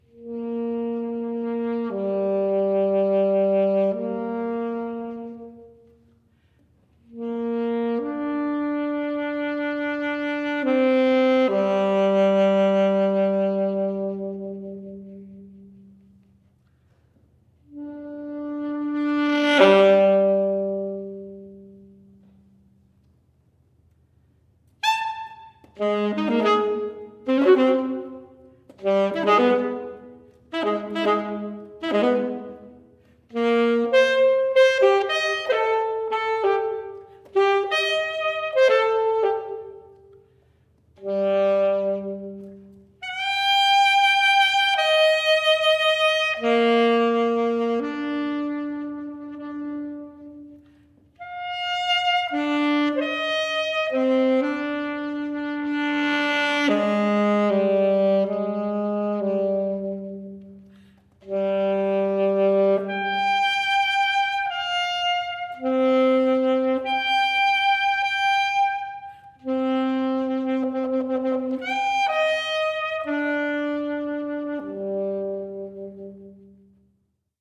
for solo alto saxophone
Weighted possibilities of available pitches on the saxophone and sets of predetermined rhythmic material were the basis of the computer-generated material. The piece alternates improvisatory sections with computer-generated sections.